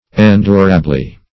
endurably - definition of endurably - synonyms, pronunciation, spelling from Free Dictionary Search Result for " endurably" : The Collaborative International Dictionary of English v.0.48: Endurably \En*dur"a*bly\, adv.